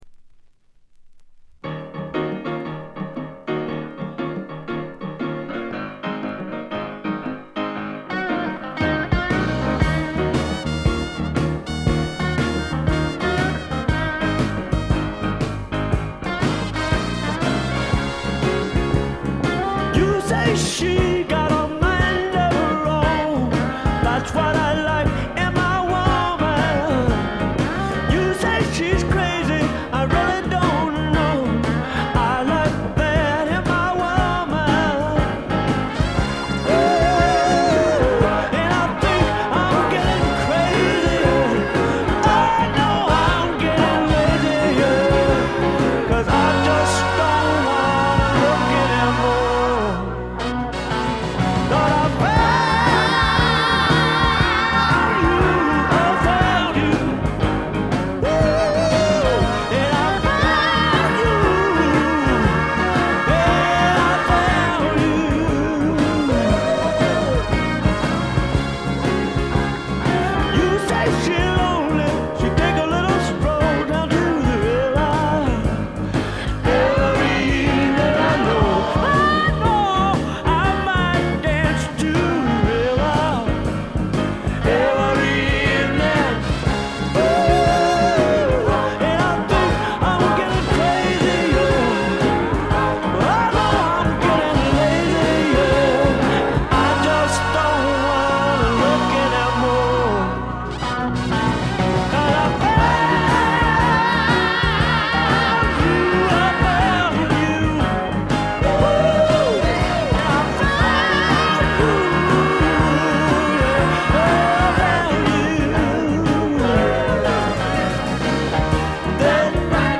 わずかなノイズ感のみ。
泣けるバラードからリズムナンバーまで、ゴスペル風味にあふれたスワンプロック。
試聴曲は現品からの取り込み音源です。